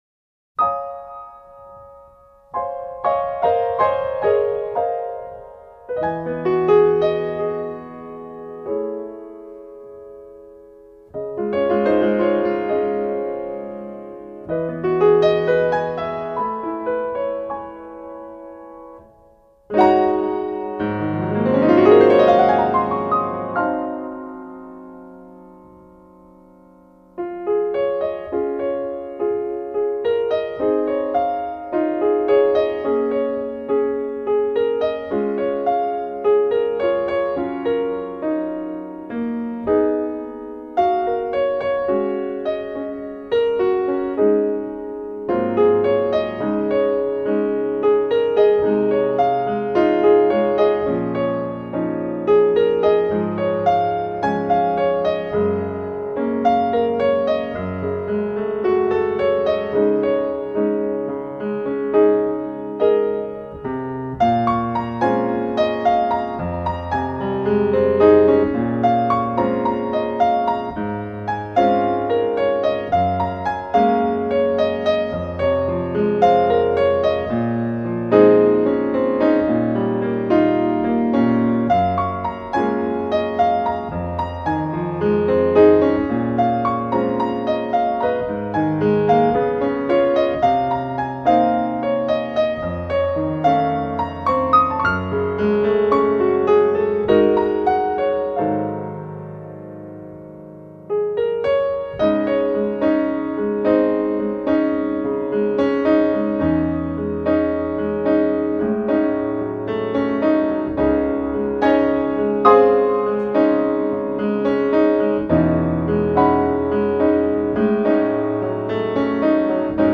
专辑艺术家: Piano  Collection